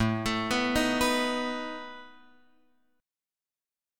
A Suspended 2nd Flat 5th